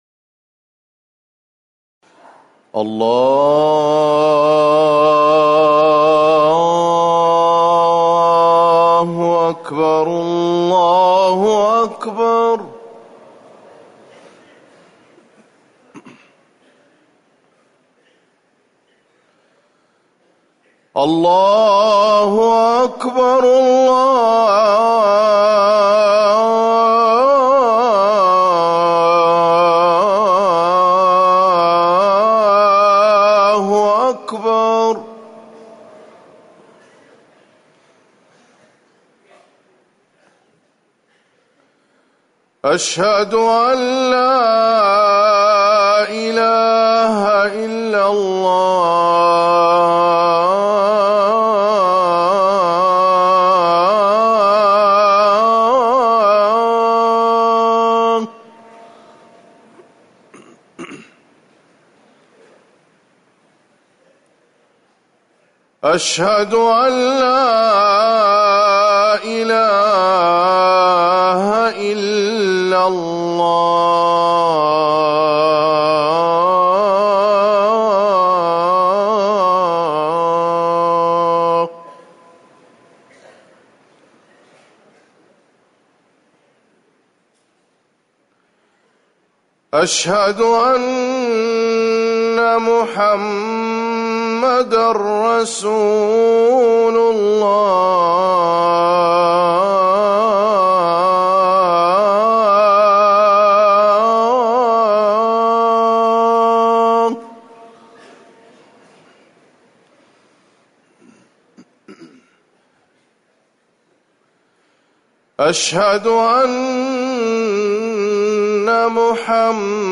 أذان الفجر الثاني
تاريخ النشر ٤ محرم ١٤٤١ هـ المكان: المسجد النبوي الشيخ